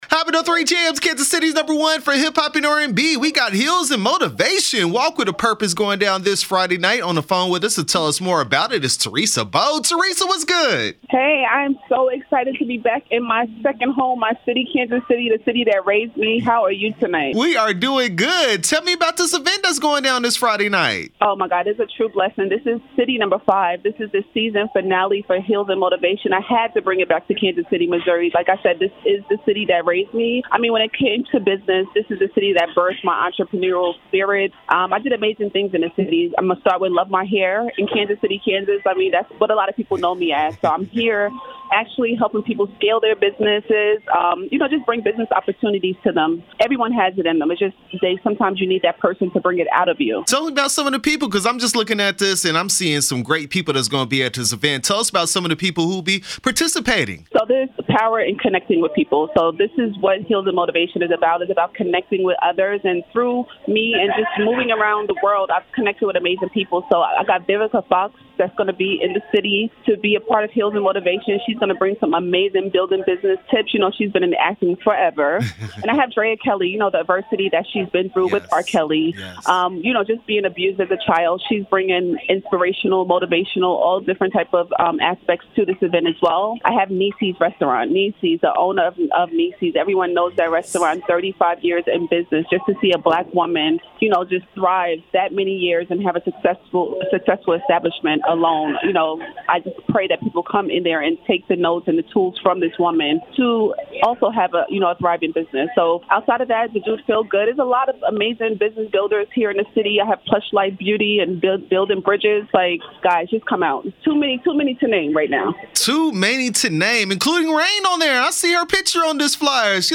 Heels & Motivation interview 12/2/21